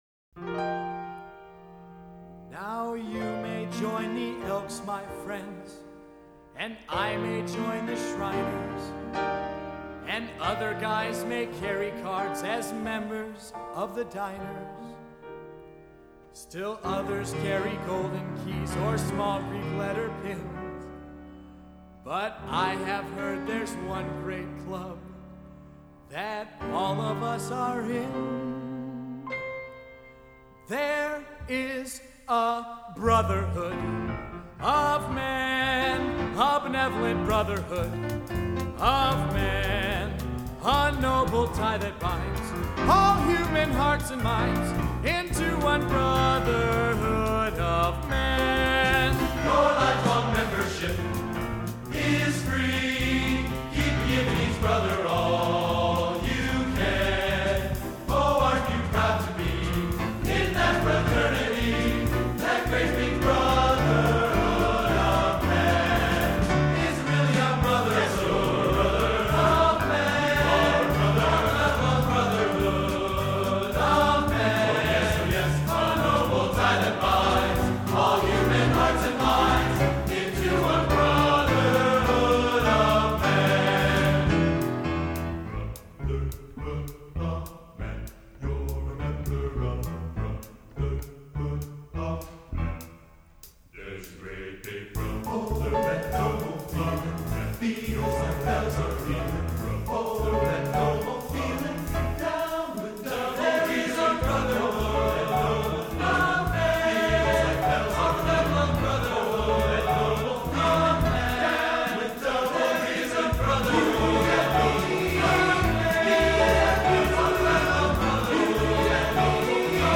Genre: | Type: Studio Recording